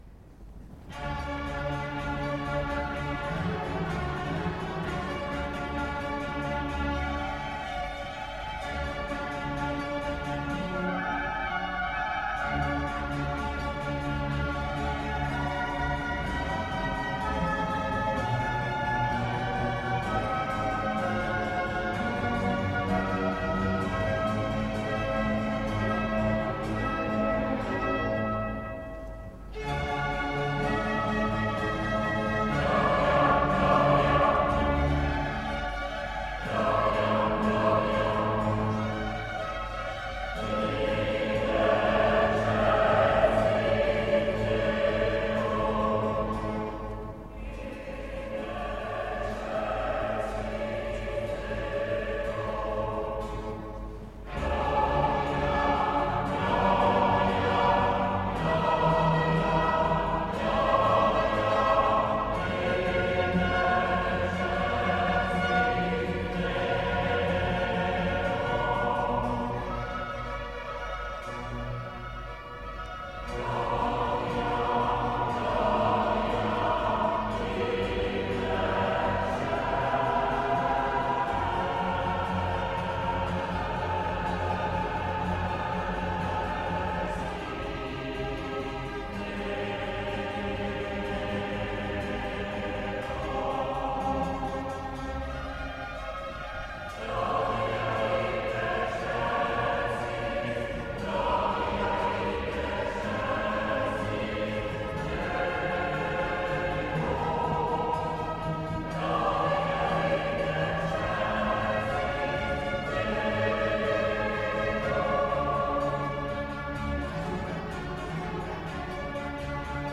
Il ne me reste plus d'exploitable qu'un Gloria de Vivaldi, donné à Saint-Brieuc en 1972 avec le Chœur Renaissance.
Mais si, je vous assure, il y a bien une partie de flûte et c'est moi qui la joue. Il faut toutefois de bonnes oreilles pour m'entendre car la flûte double presque en permanence les premiers violons ou le hautbois.